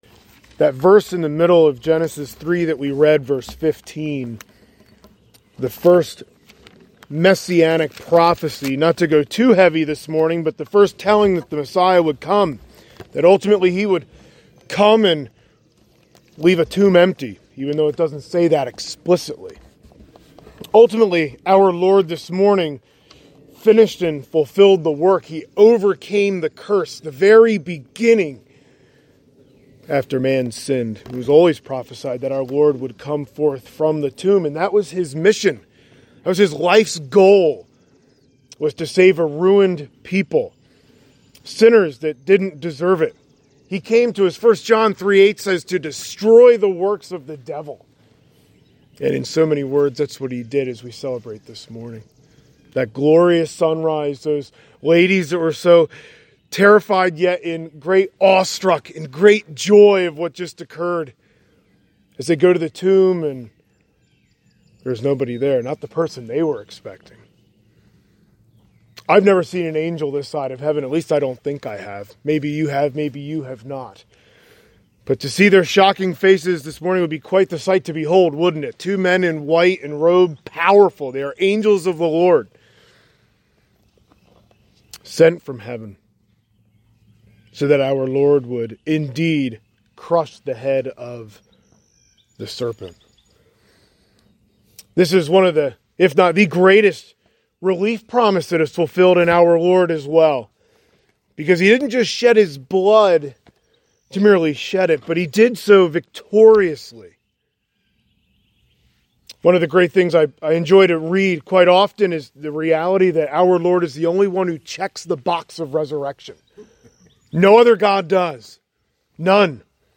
Current Sermon
Sunrise Service